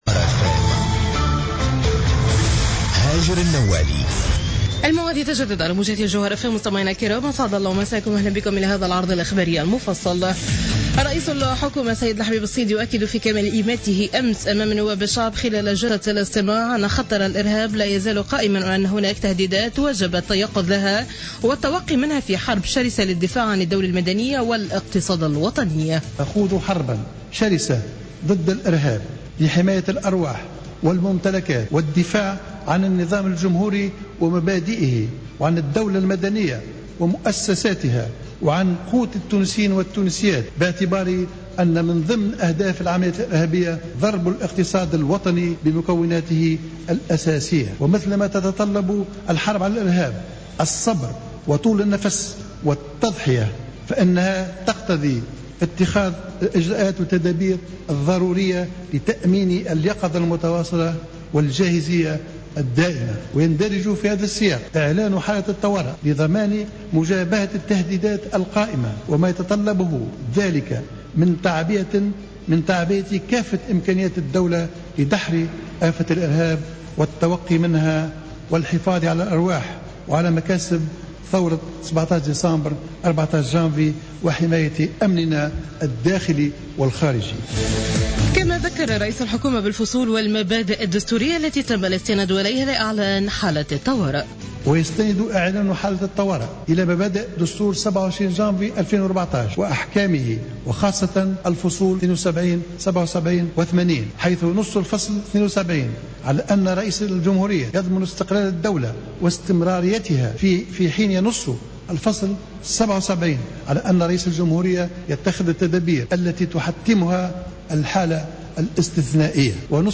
نشرة أخبار منتصف الليل ليوم الخميس09 جويلية 2015